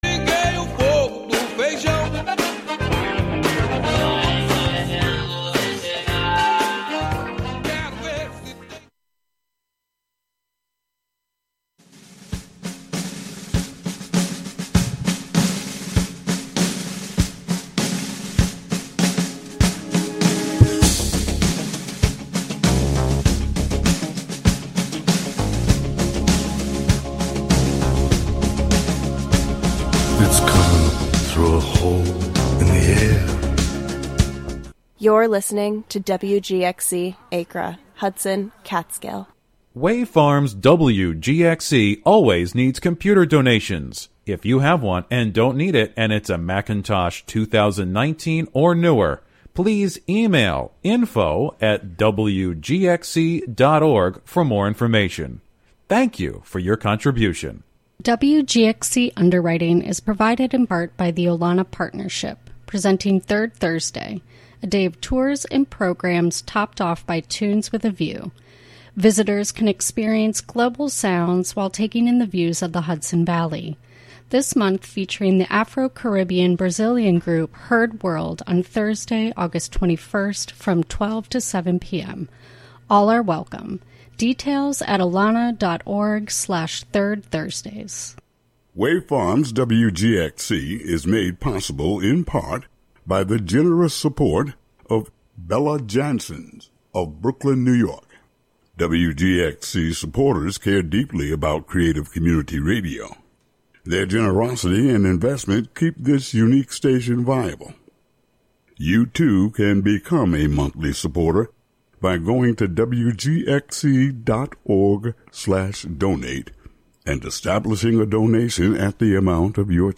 reads tarot for guests on air and plays whatever she wants